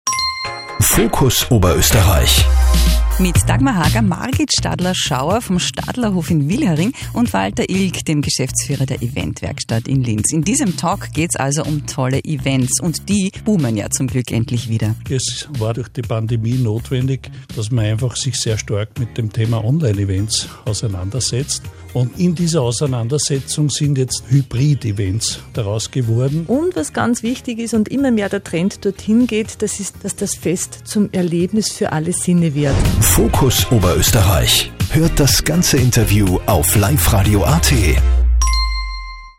Brancheninfos zum Anhören: Life Radio Spot